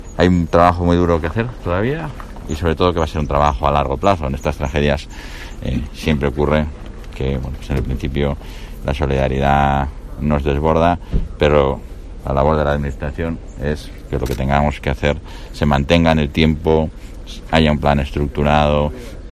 Han sido declaraciones de Igea durante su visita al castro de Ulaca, también dañado por las llamas.